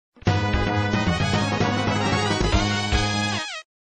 Download Street Fight sound effect for free.